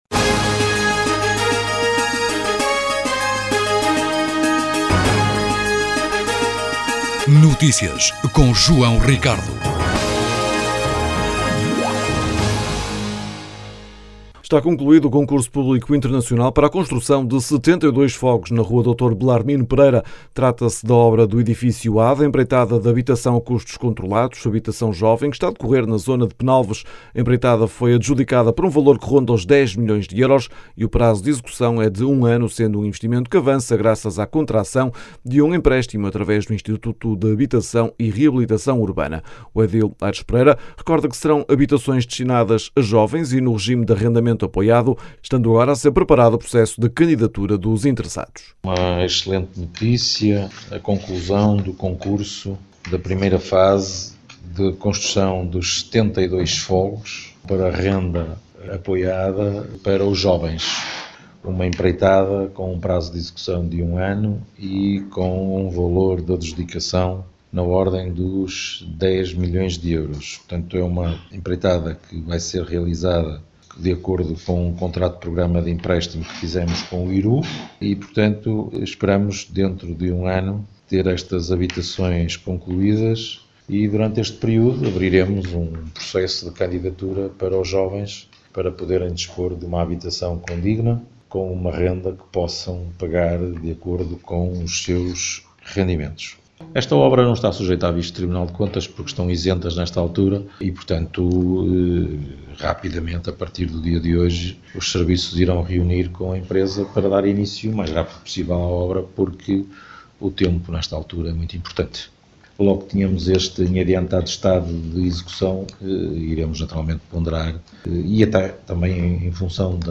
As declarações podem ser ouvidas na edição local.